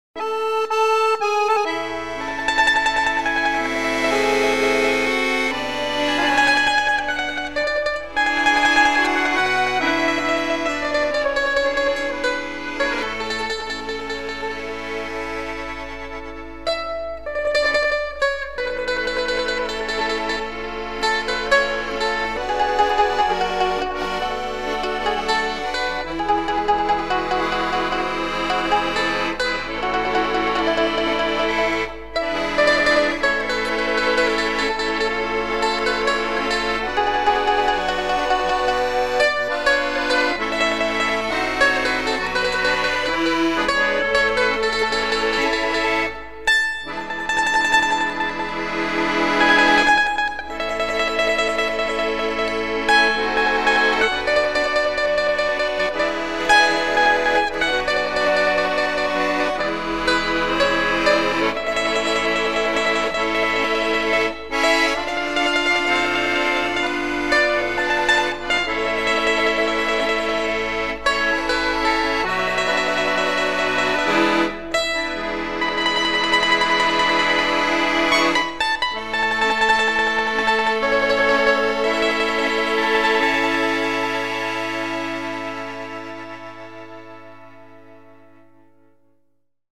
Pranzo/Cena - Fisarmonica & Mandolino